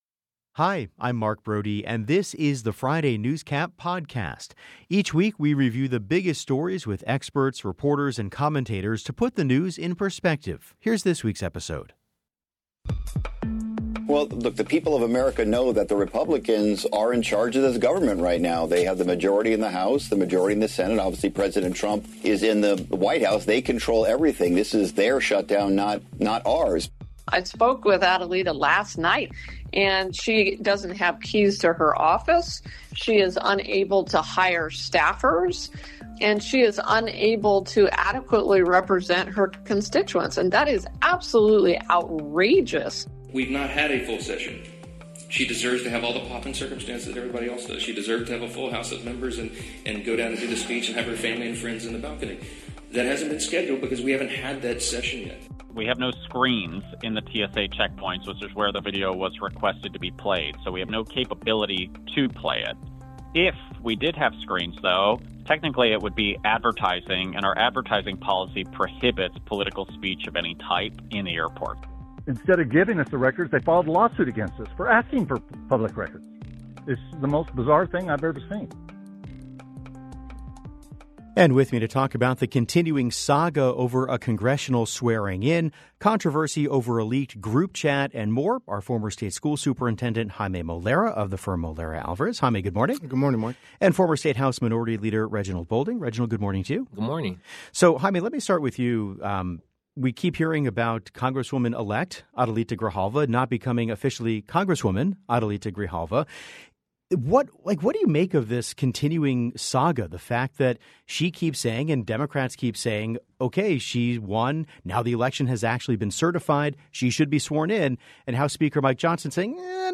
To talk about the continuing saga over a congressional swearing-in, controversy over a leaked group chat and more, The Show sat down with Reginald Bolding and Jaime Molera.